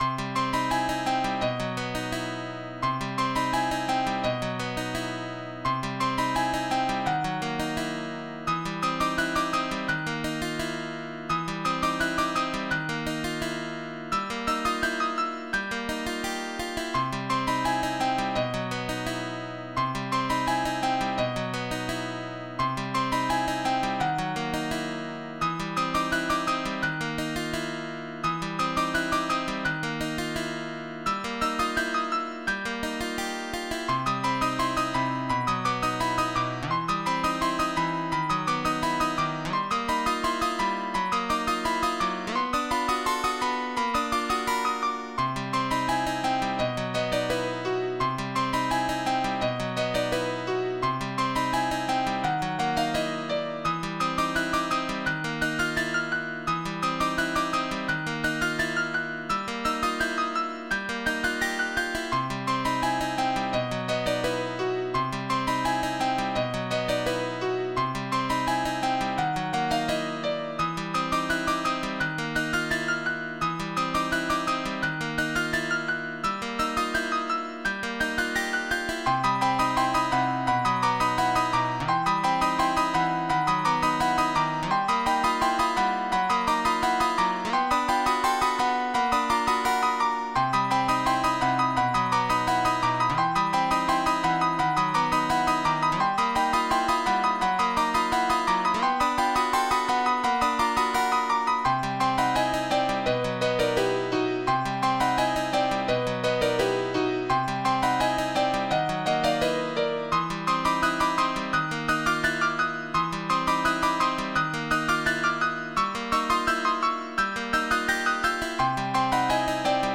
Strange dark song